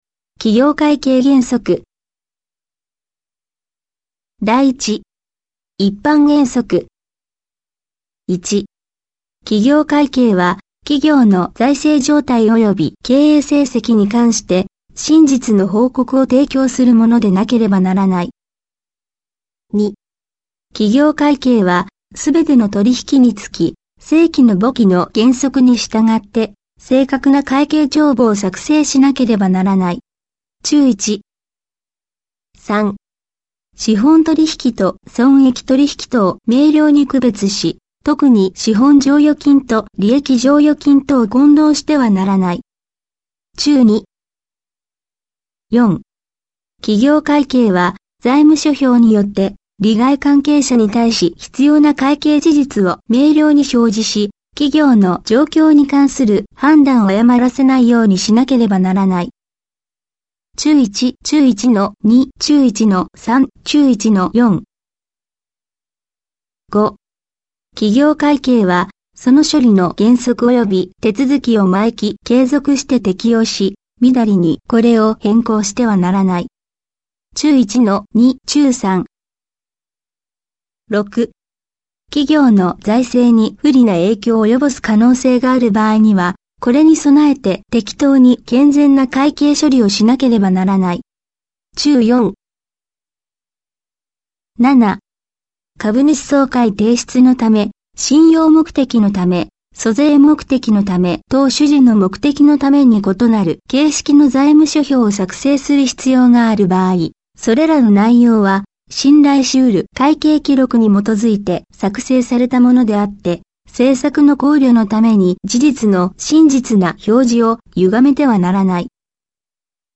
テキストをソフトに読み込ませ、再生するだけでこのような流暢な言葉でしゃべってくれます。